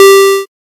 safe-2.ogg.mp3